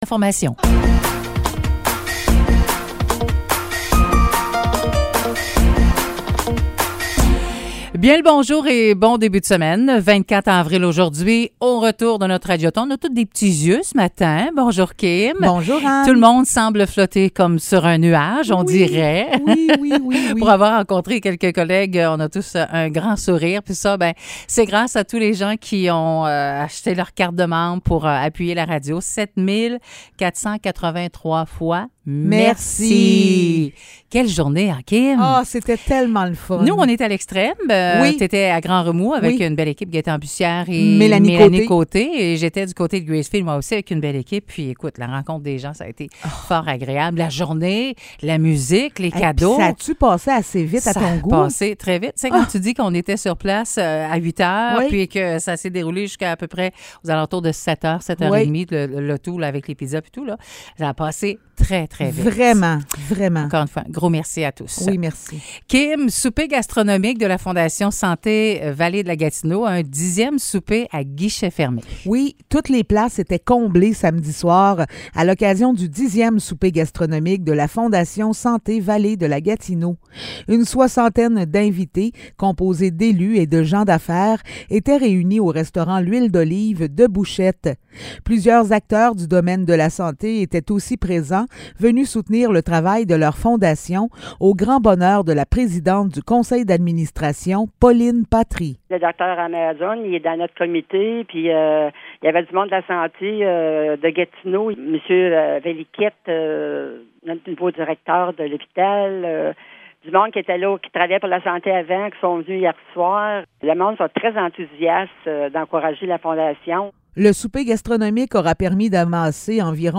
Nouvelles locales - 24 avril 2023 - 9 h